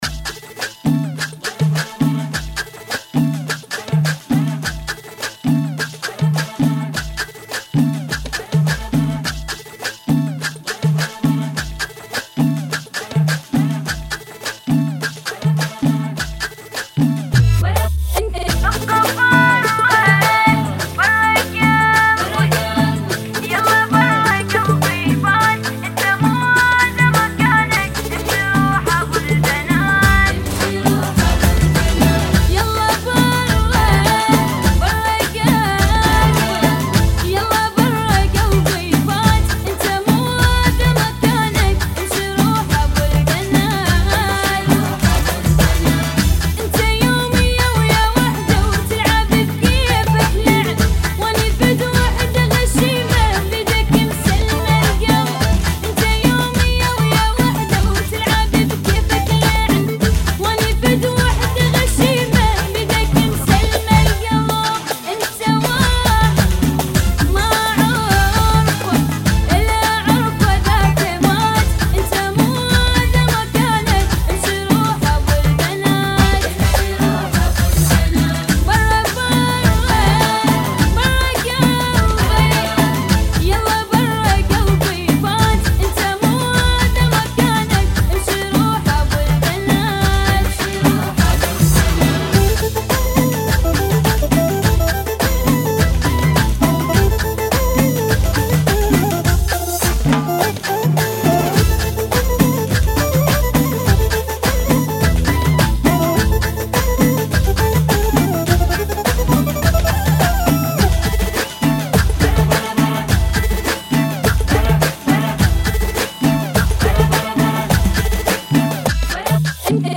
(104 BPM)